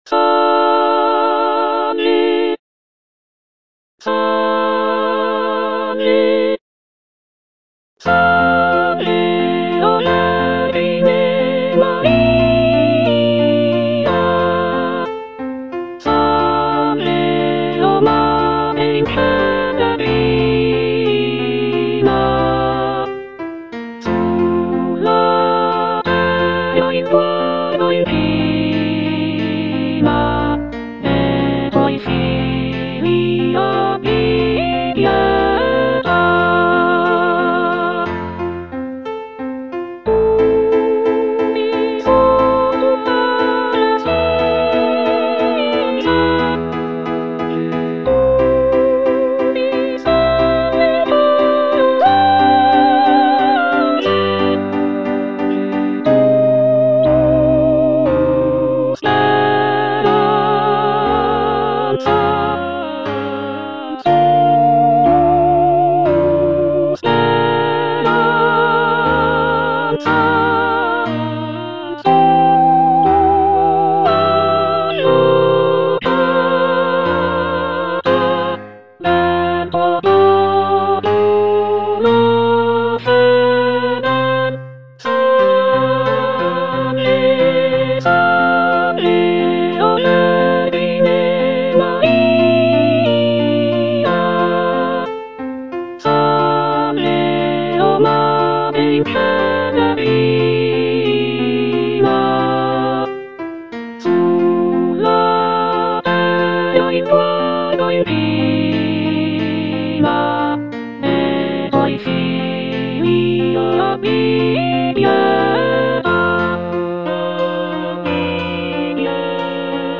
G. ROSSINI - SALVE O VERGINE MARIA (EDITION 2) Soprano (Emphasised voice and other voices) Ads stop: auto-stop